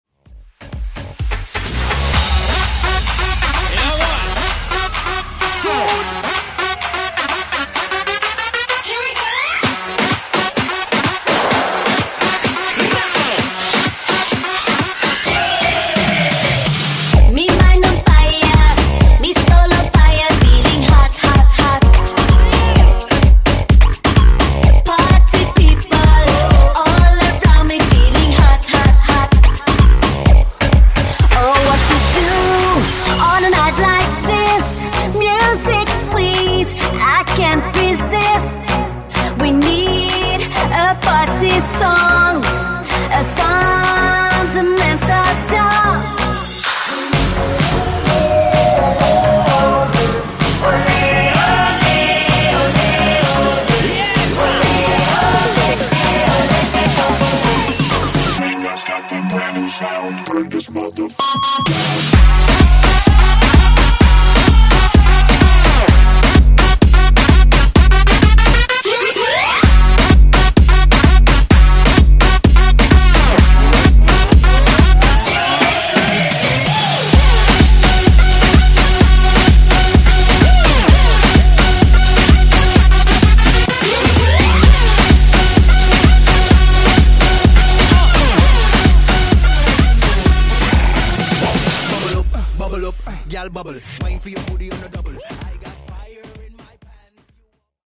Genre: RE-DRUM
Clean BPM: 115 Time